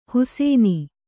♪ husēni